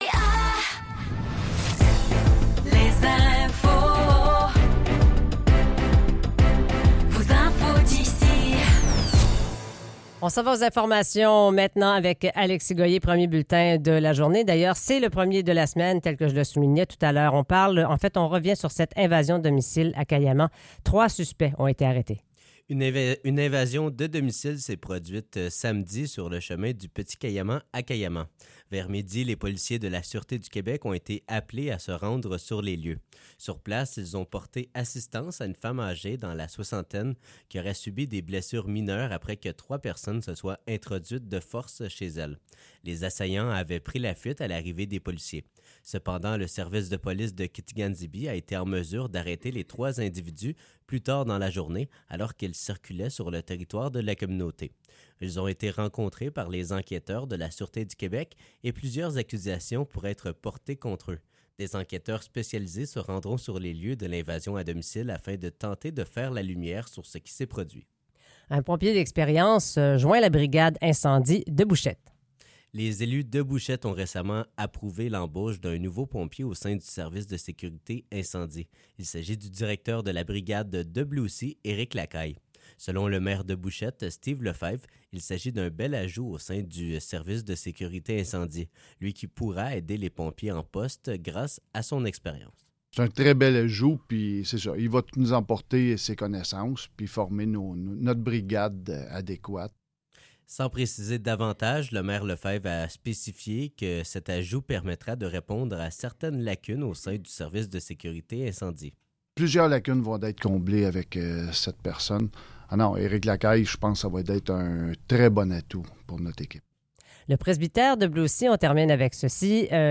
Nouvelles locales - 22 avril 2024 - 7 h